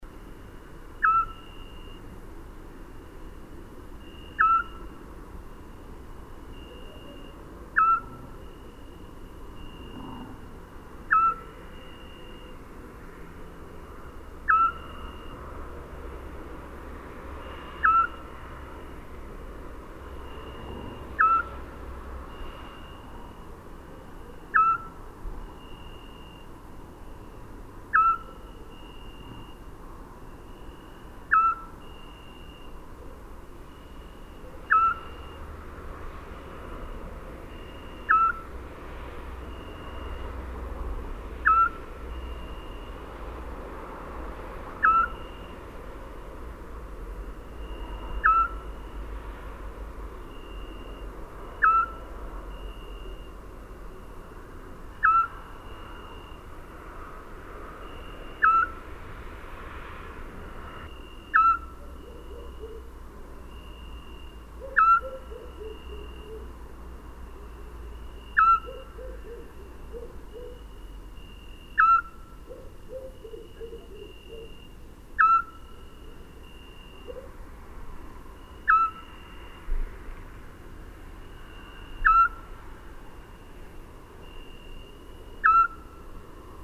Chant-hibou-petit-duc.mp3